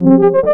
hand mining